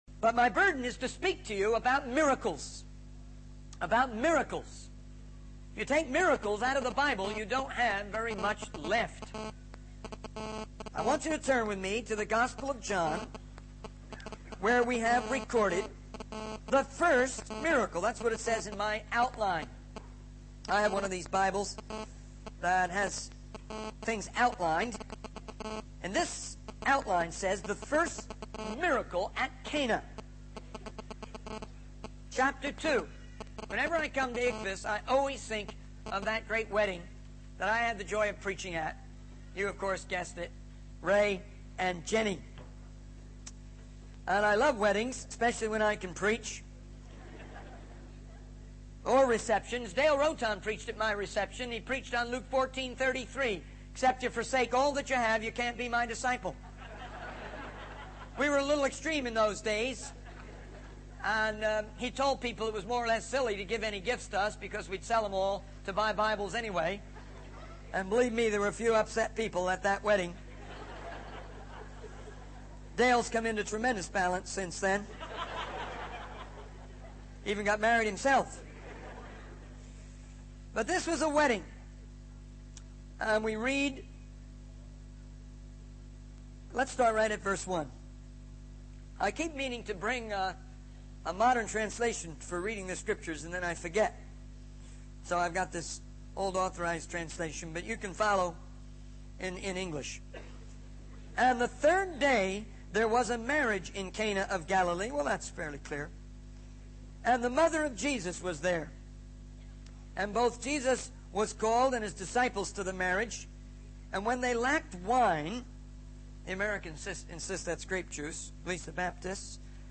In this sermon, the speaker emphasizes the importance of listening and truly hearing what others, including our spouses, are trying to communicate to us. He acknowledges that many people, especially as they get older, struggle with stability in their marriages and ministries.